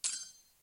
monedas.ogg